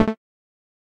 Techmino/media/effect/chiptune/selector.ogg at 43e2caa30ef40066a5bc223e999d2669e674ead9
selector.ogg